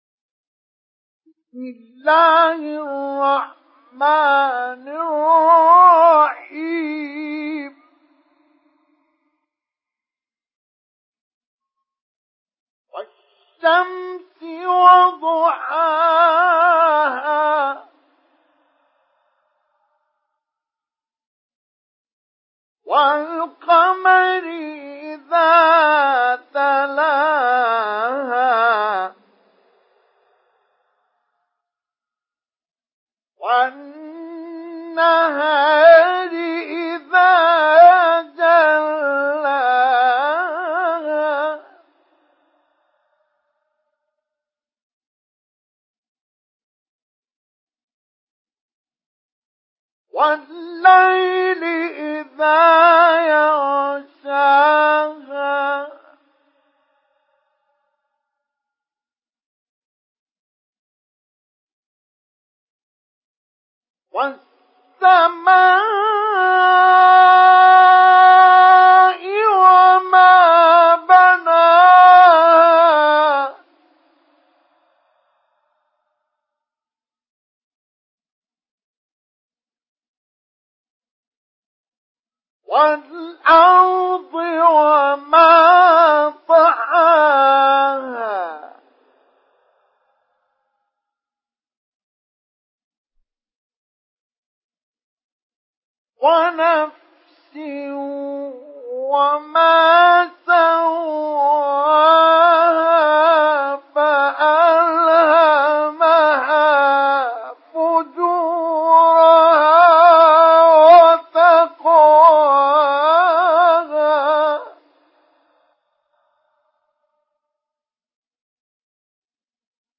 Surah الشمس MP3 in the Voice of مصطفى إسماعيل مجود in حفص Narration
Listen and download the full recitation in MP3 format via direct and fast links in multiple qualities to your mobile phone.